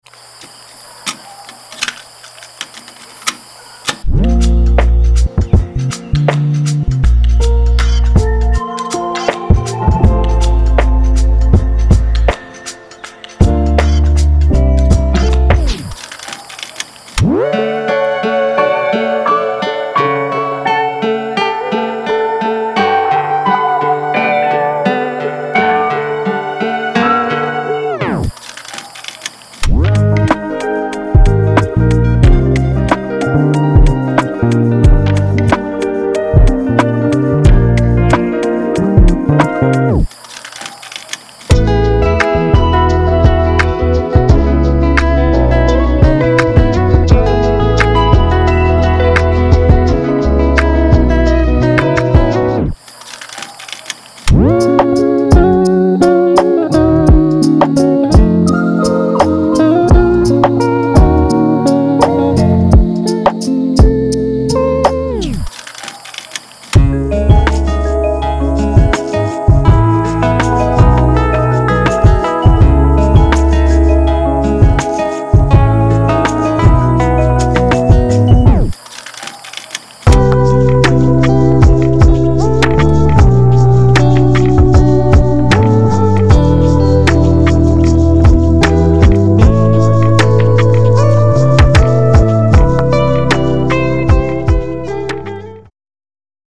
Genre:Lo-Fi Hip Hop
デモサウンドはコチラ↓